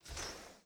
WindowOpen.wav